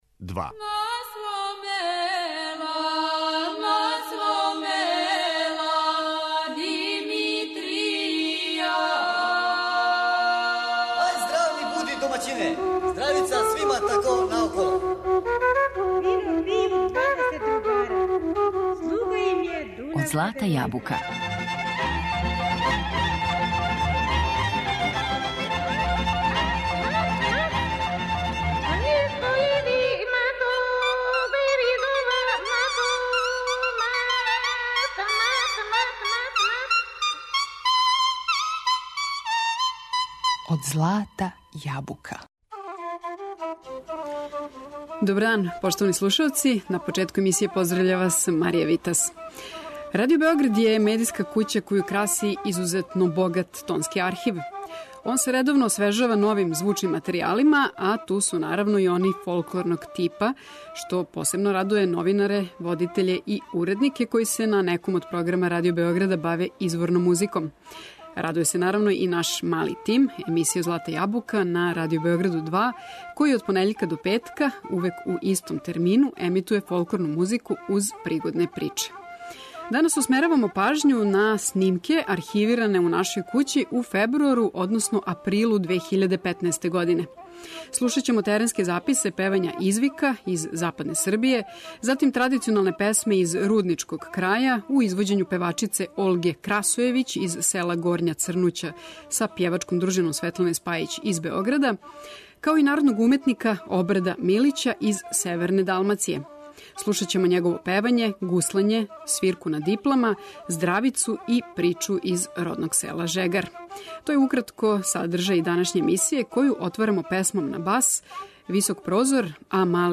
теренске записе певања извика
диплара, гуслара и здравичара из северне Далмације